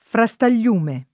frastagliume [ fra S tal’l’ 2 me ] s. m.